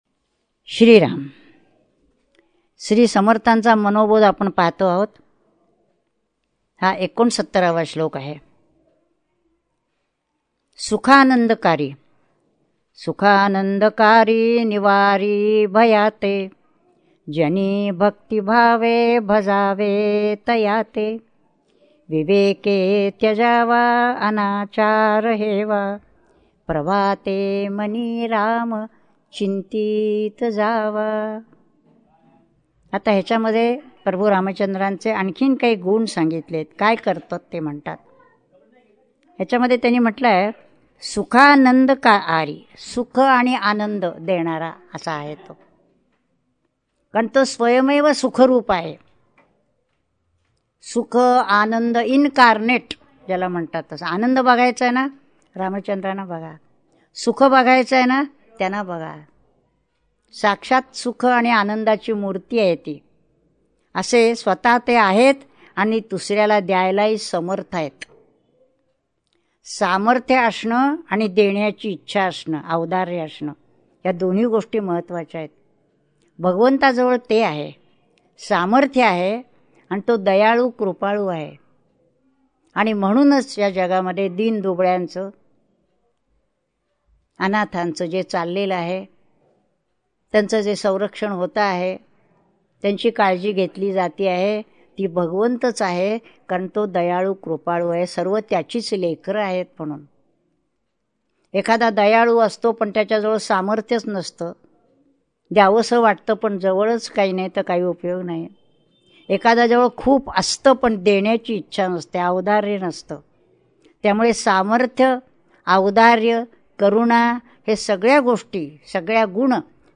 श्री मनाचे श्लोक प्रवचने श्लोक 69 # Shree Manache Shlok Pravachane Shlok 69